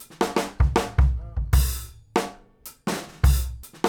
GROOVE 1009L.wav